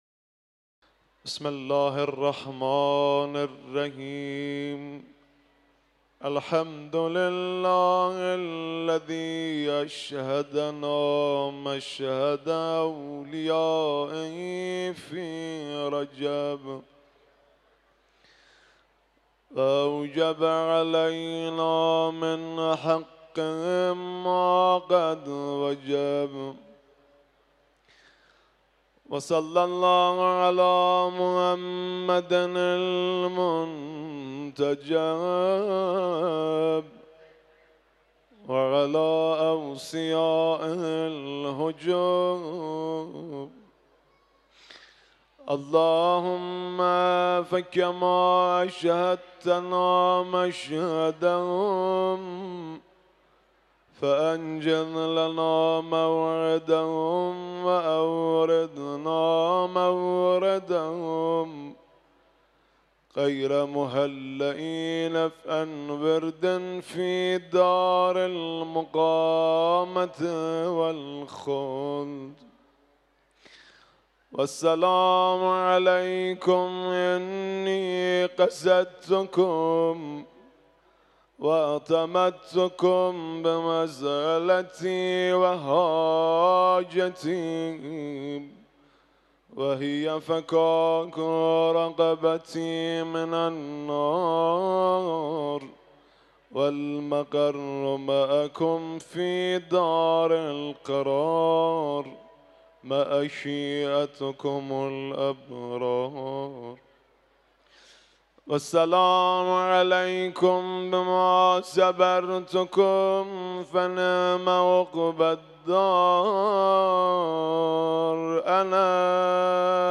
دانلود فایل صوتی «زیارت رجبیه» با نوای آقای مهدی رسولی